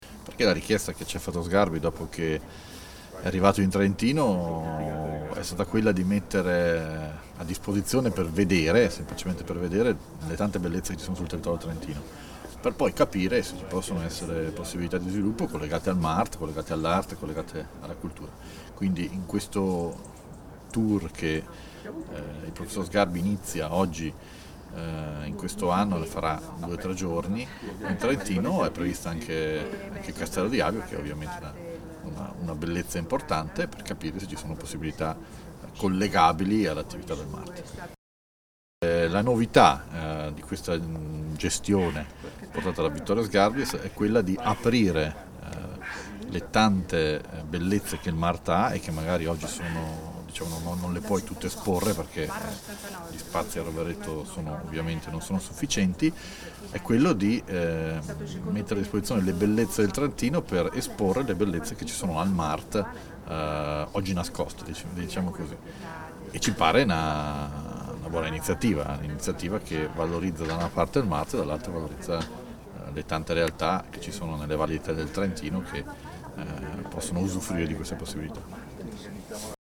Castello Avio intervista Fugatti Scarica il file (File audio/mpeg 2,45 MB) Castello Avio intervista Sgarbi Scarica il file (File audio/mpeg 3,11 MB) Castello Avio intervista sindaco Secchi Scarica il file (File audio/mpeg 545,09 kB)